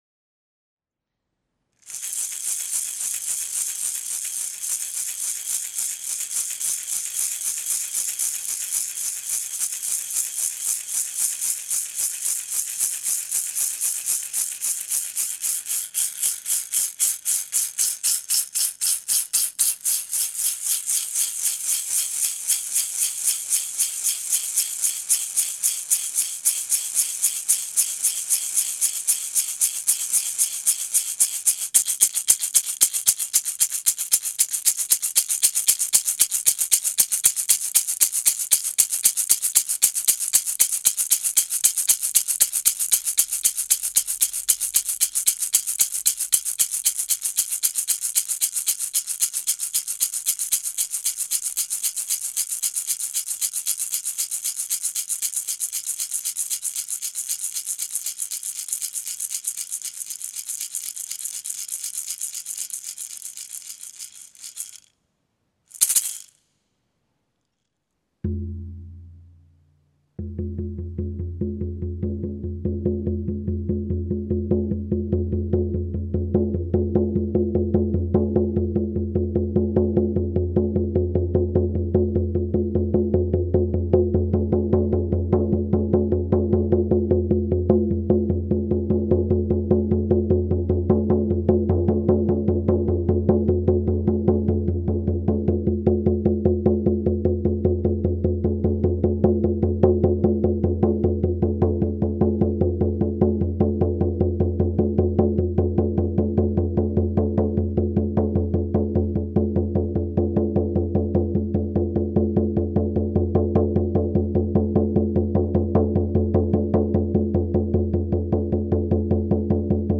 tambour_15.mp3